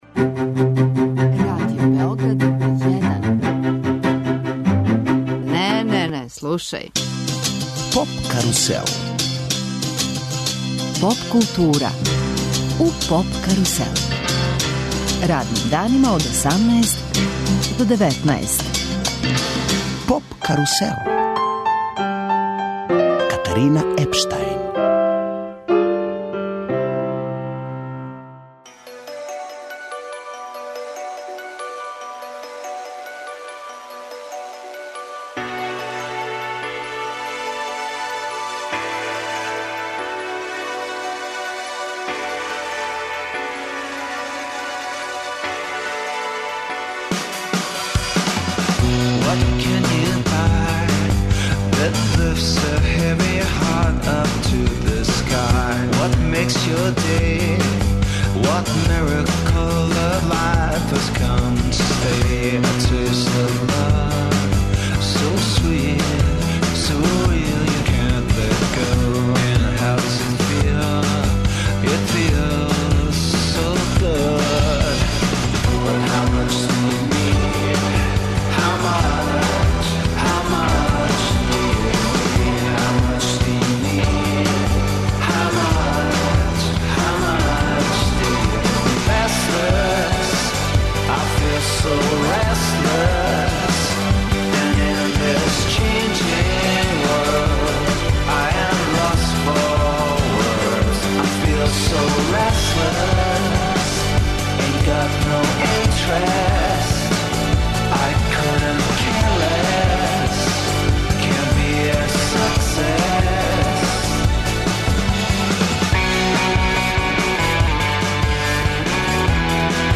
Познати њујорски продуцент и ди-џеј, Џастин Смит, познатији као Џаст Блејз, дао је, ексклузивно, интервију за наш програм.